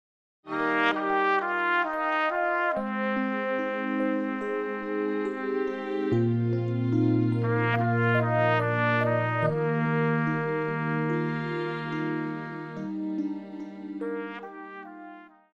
Pop,Christian
Trumpet
Band
Instrumental
Ballad
Only backing